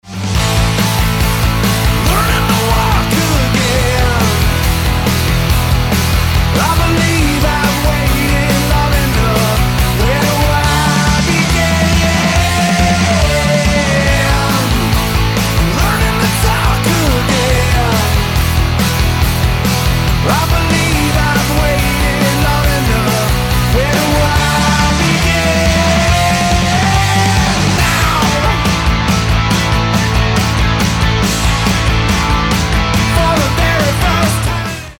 merická rocková skupina